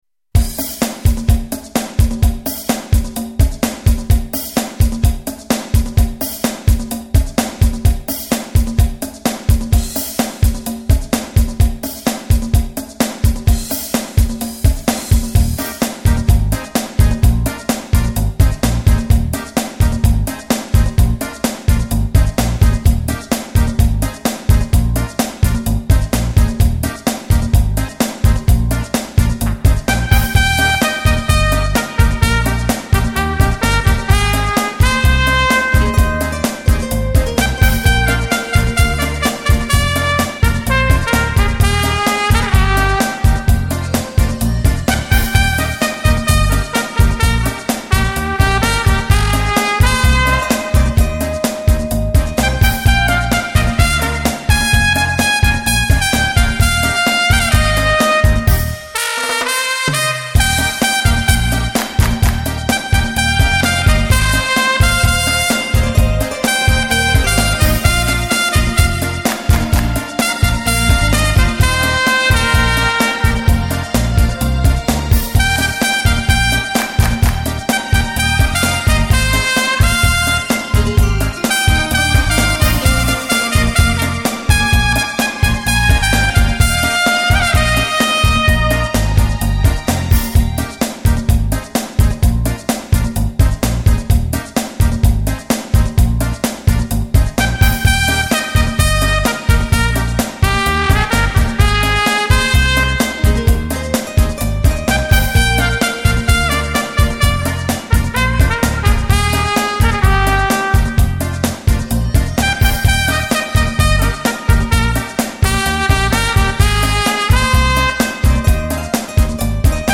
专辑格式：DTS-CD-5.1声道